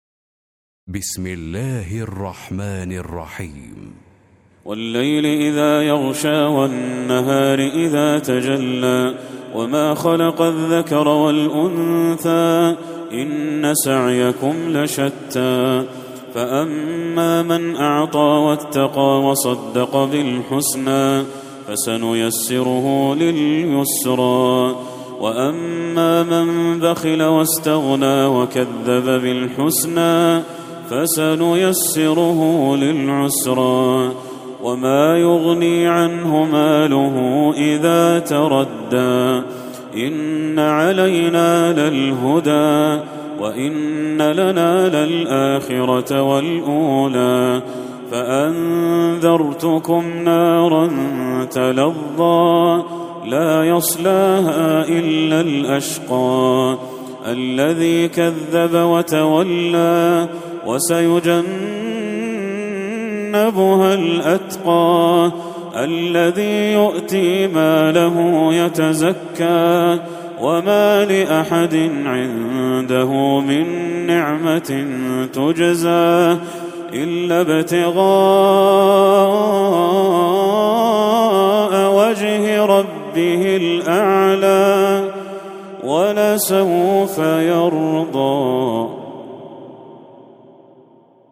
سورة الليل Surat Al-Layl > المصحف المرتل